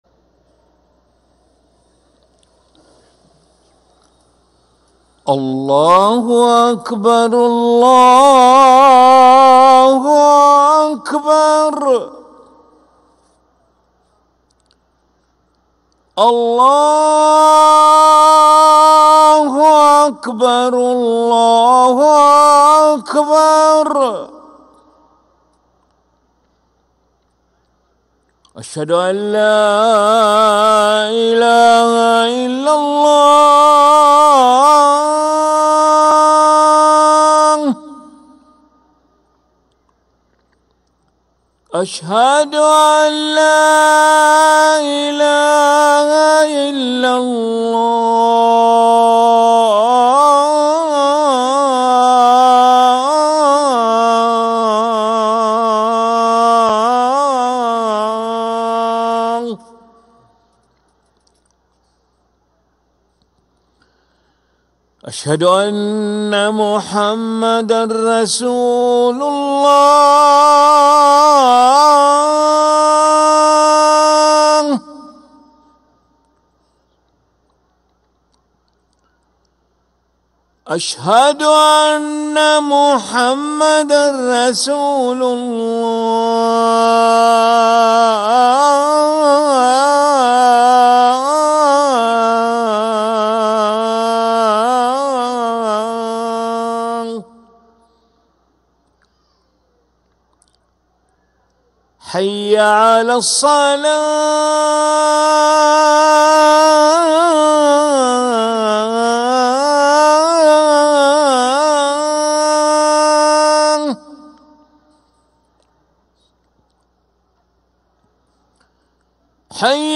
أذان العشاء للمؤذن علي ملا الخميس 26 جمادى الأولى 1446هـ > ١٤٤٦ 🕋 > ركن الأذان 🕋 > المزيد - تلاوات الحرمين